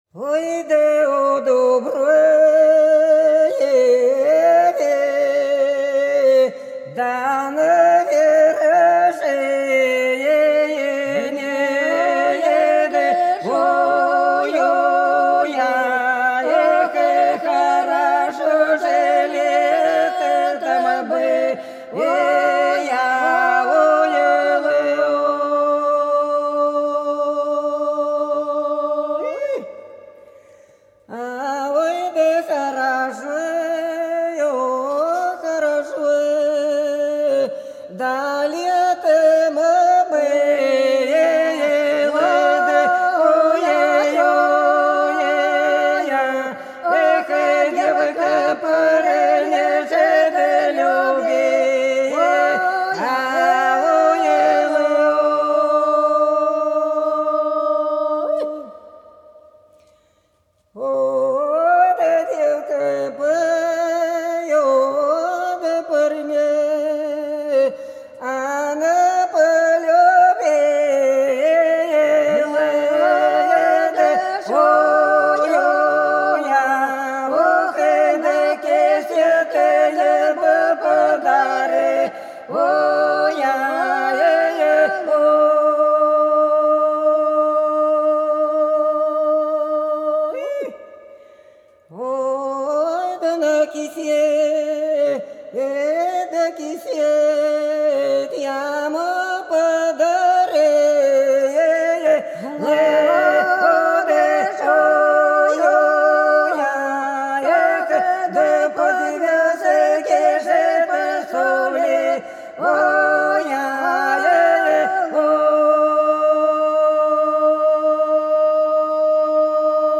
Белгородские поля (Поют народные исполнители села Прудки Красногвардейского района Белгородской области) У дуброве на вершине - протяжная, весновая